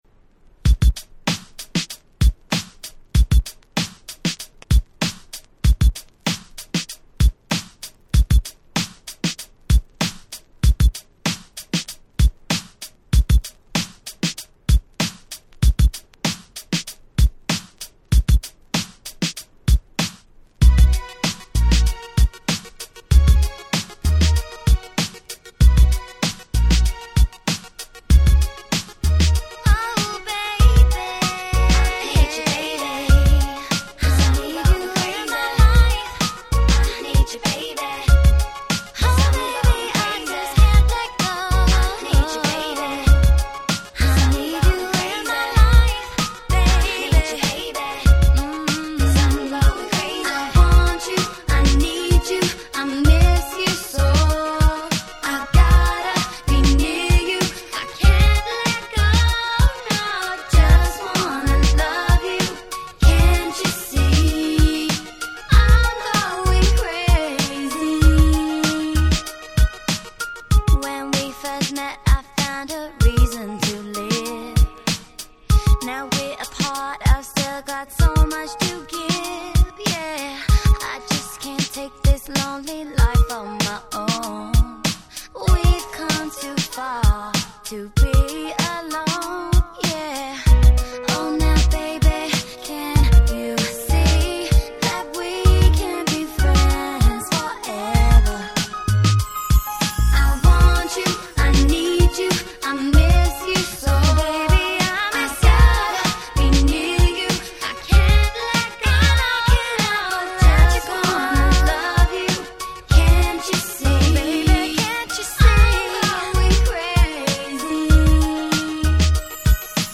06' Nice UK R&B !!
オリジナルよりも若干メインストリーム系に寄せたキラキラ系のRemixで、これはこれで結構良いです。
非常にキャッチーで女子ウケも良さそう！